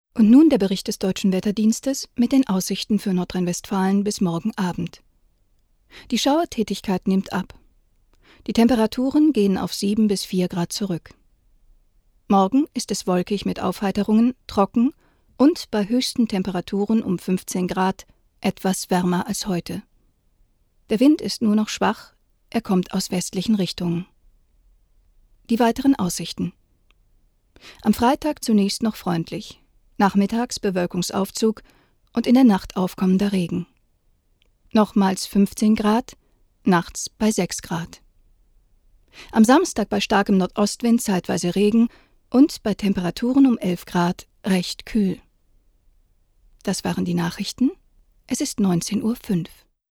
Erfahrene vielseitige Sprecherin. Stimmalter zwischen jung und mittel einsetzbar. Klare deutliche Stimme-
Sprechprobe: Industrie (Muttersprache):